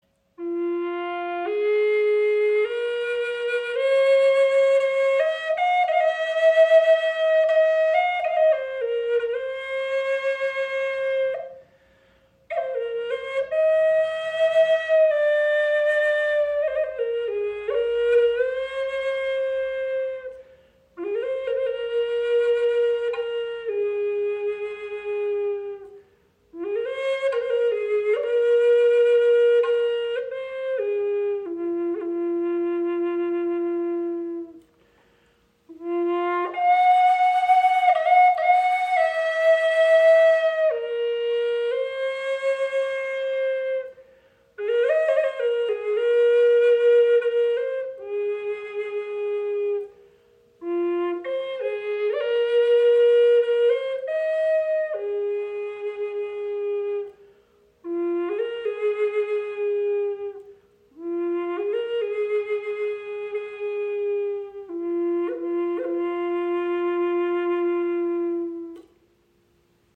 Adler Gebetsflöte in F-Moll | Eichenholz | Redheart Holz • Raven Spirit
• Icon Gesamtlänge 61  cm, 25  mm Innenbohrung – kraftvolle Präsenz
Präzise gestimmt und harmonisch ausbalanciert entfaltet die Flöte einen vollen, warmen und lebendigen Ton, der Herz und Seele berührt.
Voller, klarer Klang für Meditation und Klangarbeit!
Ihr Klang ist voll, lebendig und klar, mit einer warmen Tiefe und kraftvoller Präsenz – ideal für Meditation, Gebetsmusik, Klangarbeit und ausdrucksstarkes, intuitives Spiel.